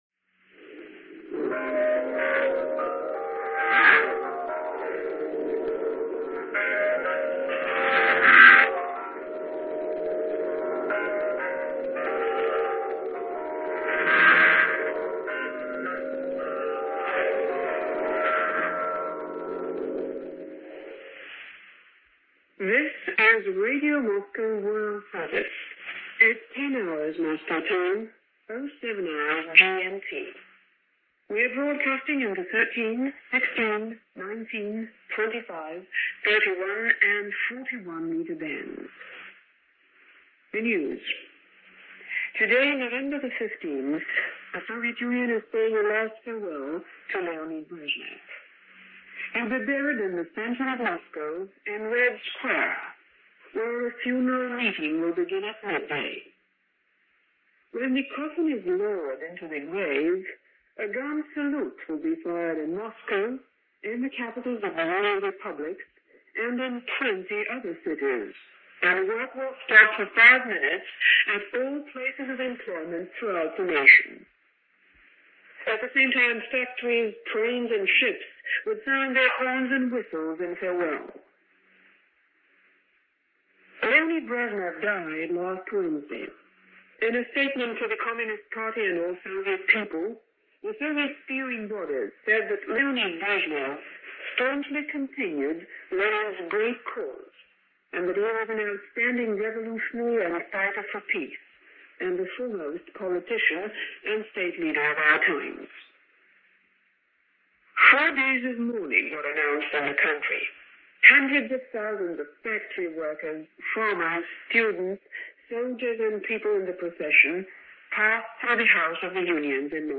November 15, 1982 - The View From Red Square - Funeral For Brezhnever - funeral of Soviet leader Leonid Brezhnev as reported by Radio Moscow.